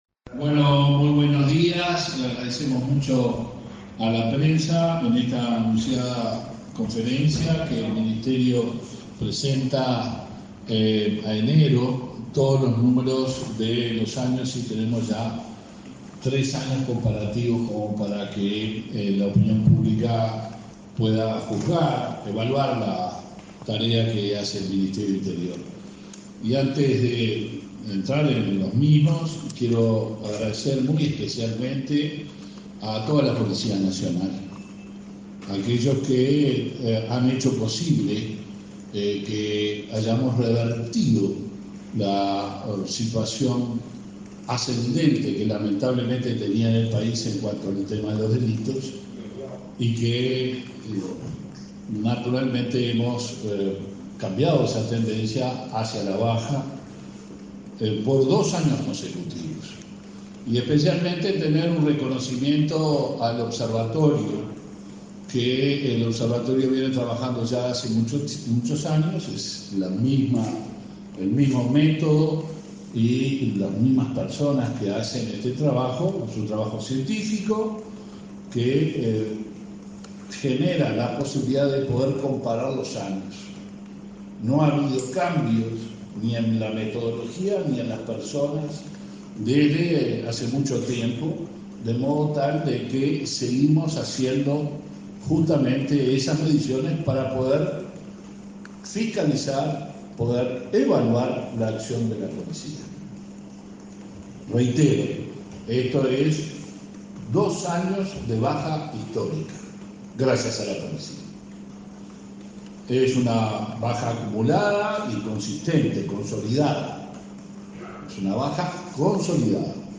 Conferencia de prensa del ministro de Interior, Luis Alberto Heber
El ministro Heber brindó una conferencia de prensa.